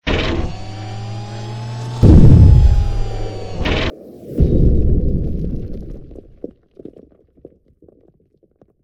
rocketgroundin.ogg